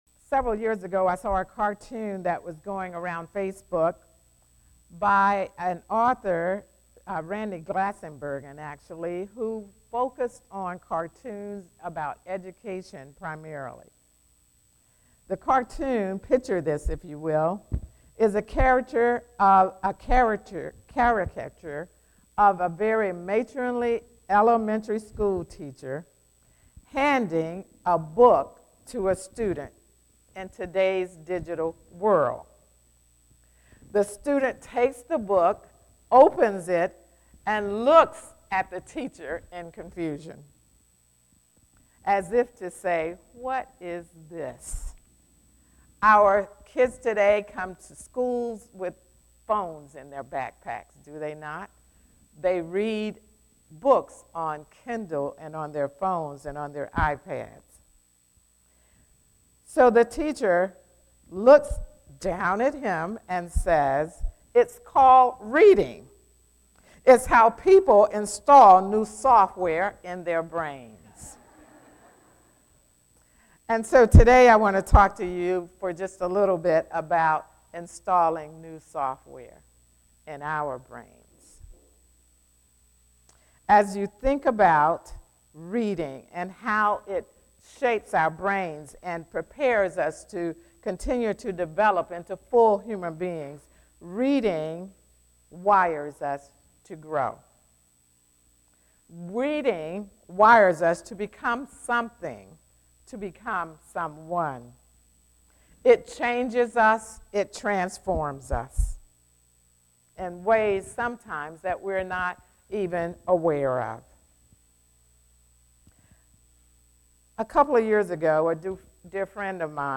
Sunday, November 3 is a special service for Lawrenceville First Christian Church.